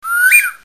bird2.mp3